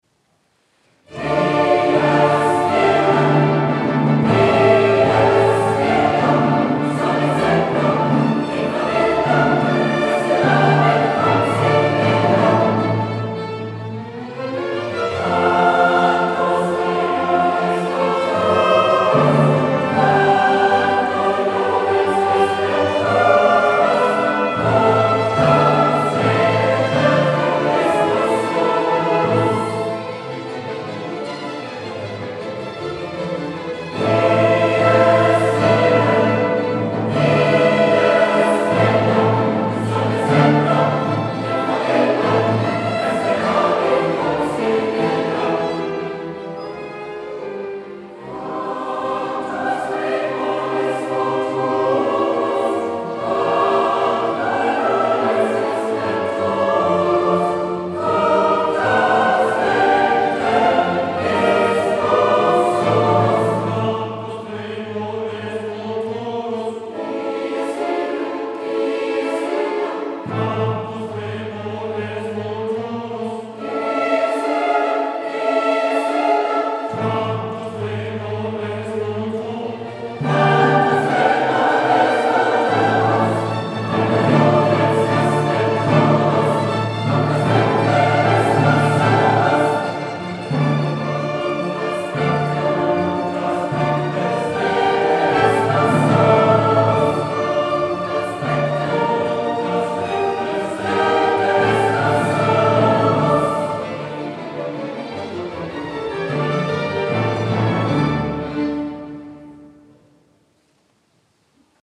Rückblick Chorkonzert „Mozart Requiem“
Es war ein wundervolles Klangerlebnis und eine ergriffene Stimmung in der St. Meinrads Kirche.